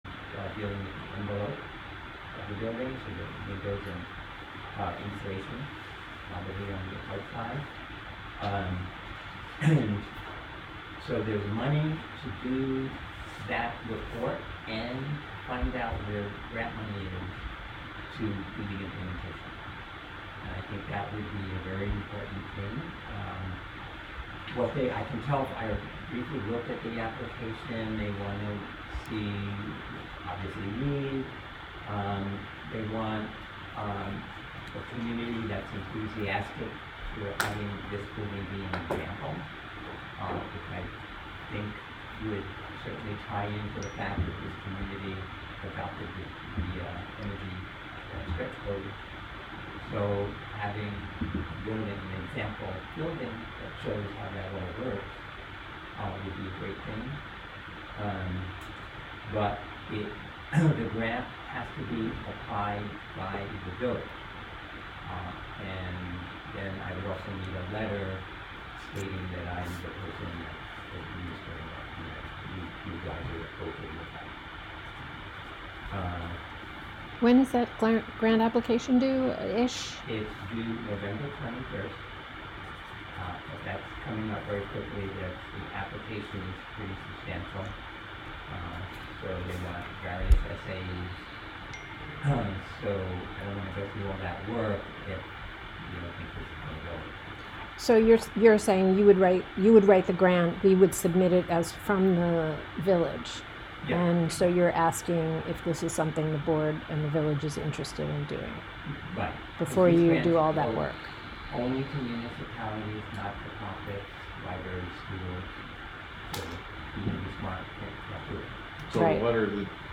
Live from the Village of Philmont: Village Board Meeting (Audio)